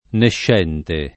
[ nešš $ nte ]